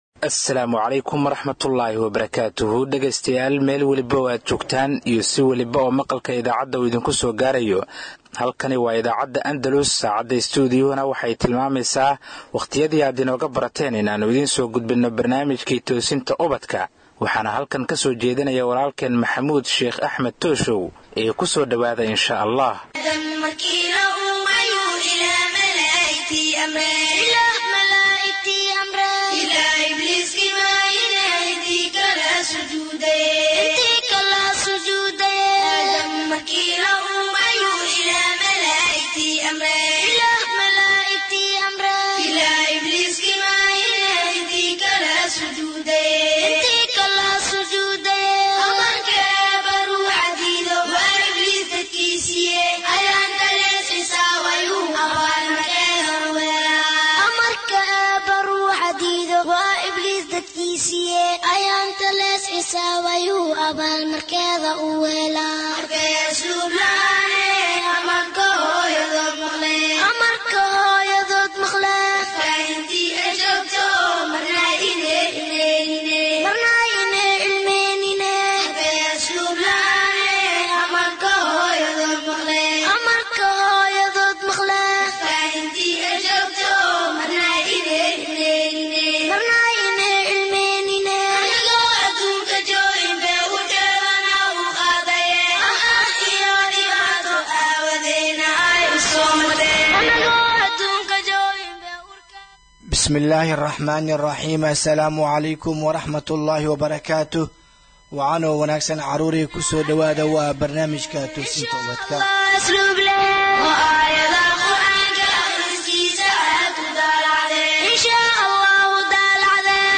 Halkan waxad ka dhagaysan kartaa barnaamijka todobaadlaha ah ee Toosinta Ubadka kaasi oo ka baxa idaacadda Andalus, barnaamijkan oo ah barnaamij ay caruurtu aad u xiisayso wuxuu ka koobanyahay dhowr xubnood oo kala ah wicitaanka iyo bandhiga caruurta, jawaabta Jimcaha, Iftiiminta qalbiga iyo xubinta su aasha toddobaadka.